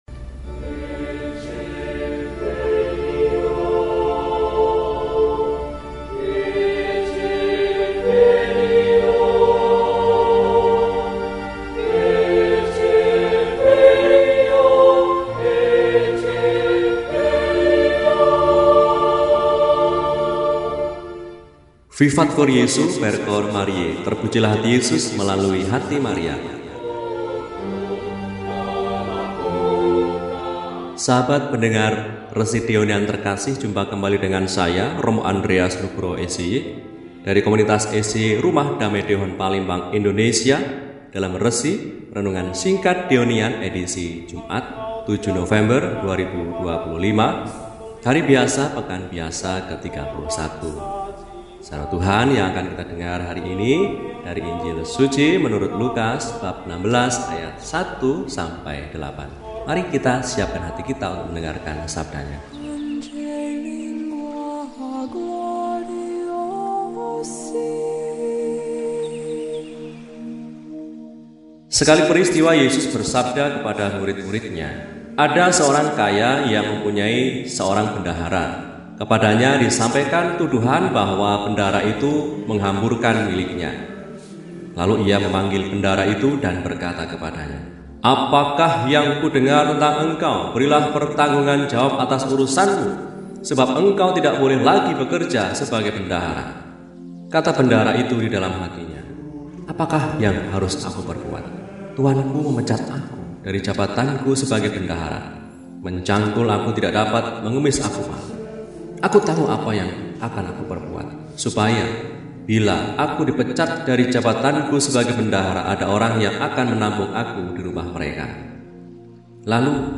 Jumat, 07 November 2025 – Hari Biasa Pekan XXXI – RESI (Renungan Singkat) DEHONIAN